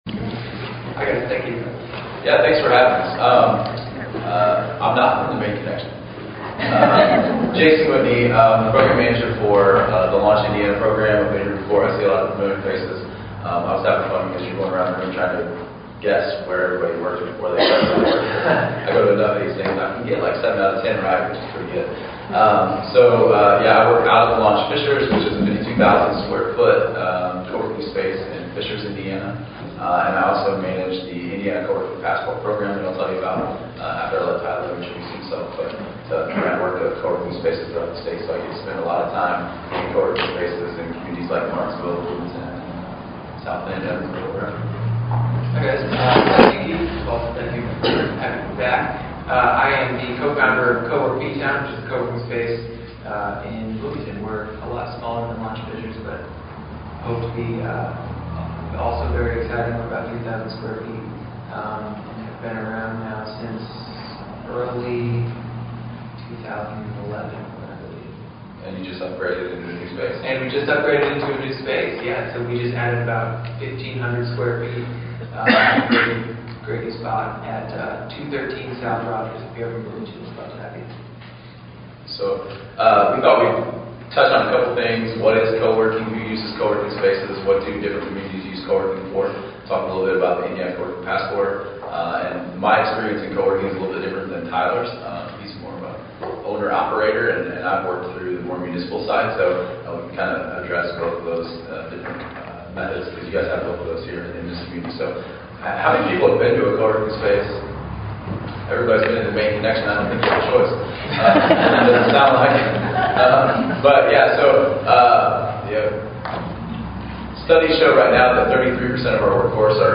Coworking highlighted at April Luncheon